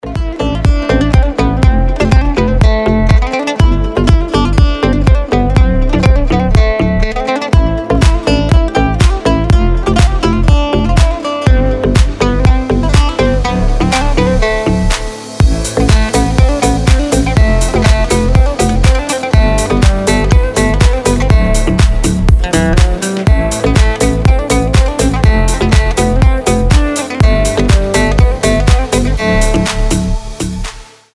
гитара
deep house
восточные мотивы
без слов
Красивый восточный deep house